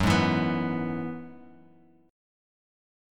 Gb7sus2sus4 chord